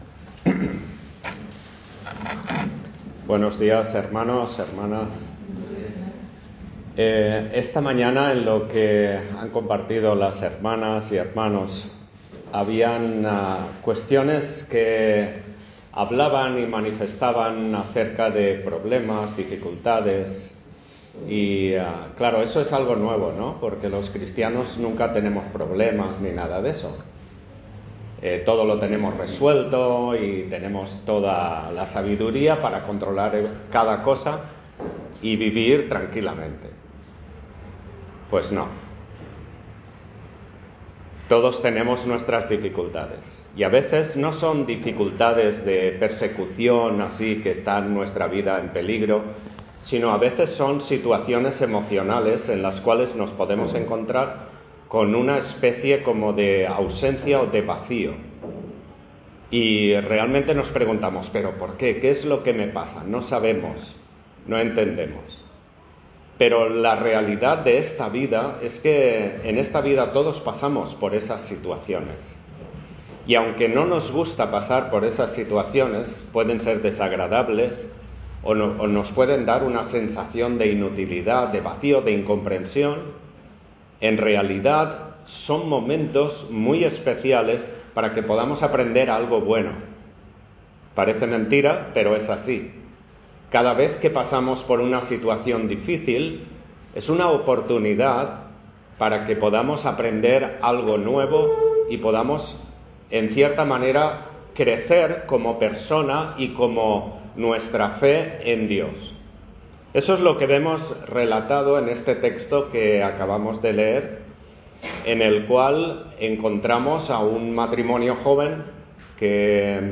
Service Type: Culto Dominical